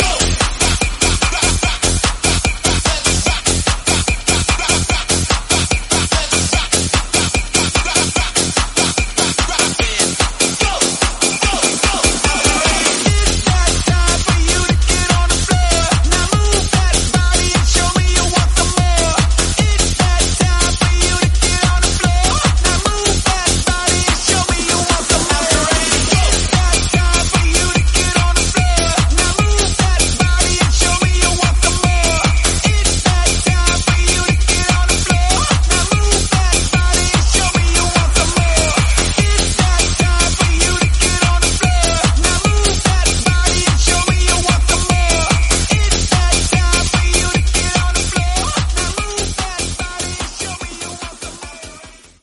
Dj Intro Outro
Genres: DANCE , RE-DRUM , TOP40